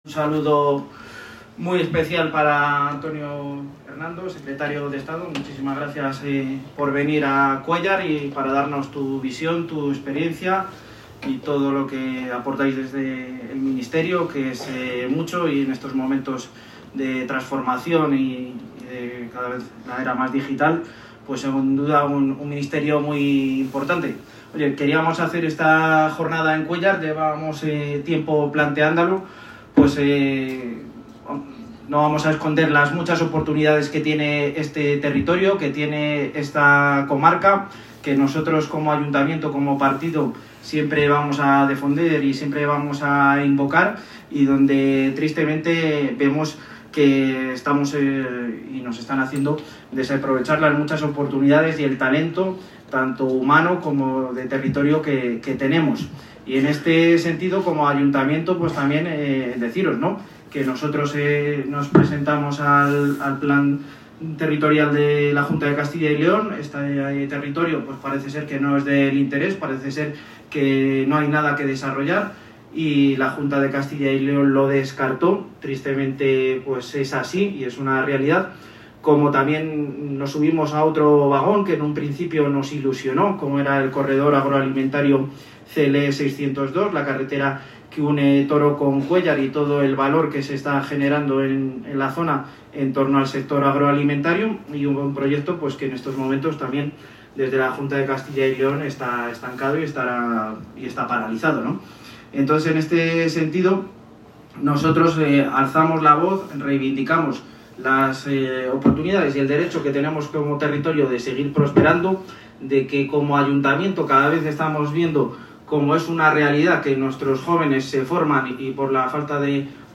La jornada fue inaugurada por el secretario de Estado de Telecomunicaciones e Infraestructuras Digitales, Antonio Hernando, el secretario general del PSOE de Segovia y diputado en el Congreso, José Luis Aceves, y el alcalde de Cuéllar, Carlos Fraile, quienes coincidieron en subrayar que la industrialización, acompañada de la digitalización, debe convertirse en una palanca imprescindible para generar empleo, fijar población y garantizar un futuro de esperanza para las comarcas segovianas.